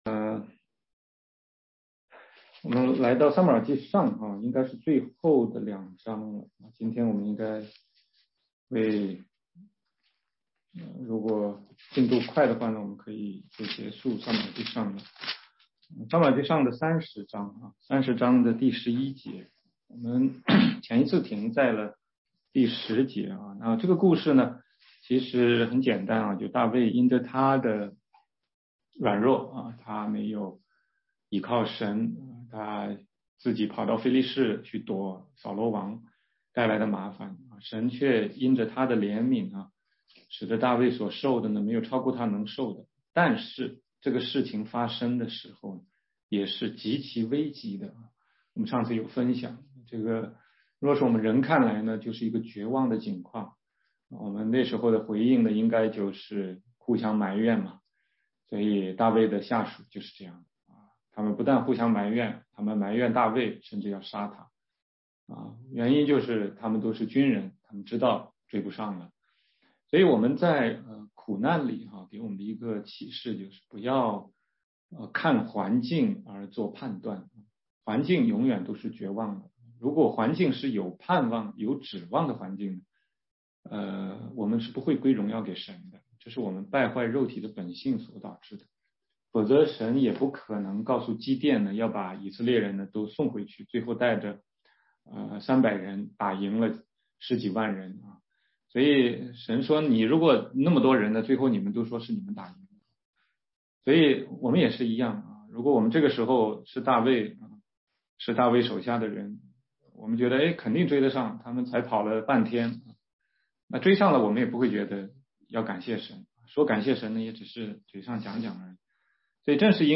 16街讲道录音 - 撒母耳记上30章11-31节：大卫夺回亚玛力人的掳物
全中文查经